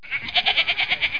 sheep.mp3